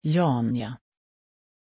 Pronunciation of Janja
pronunciation-janja-sv.mp3